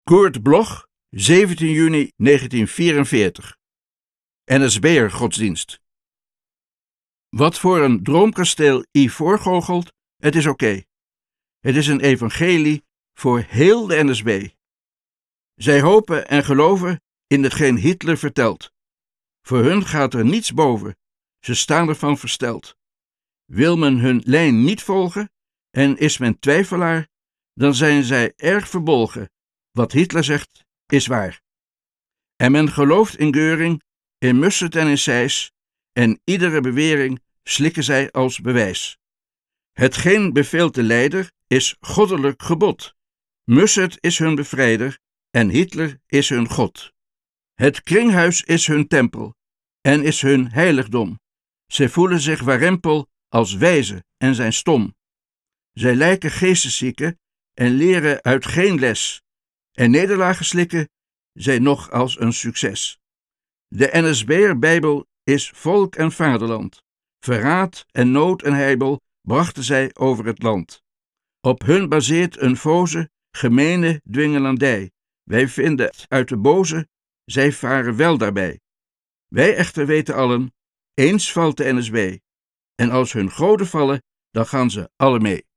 Recording: Studio Levalo, Amsterdam · Editing: Kristen & Schmidt, Wiesbaden